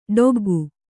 ♪ ḍoggu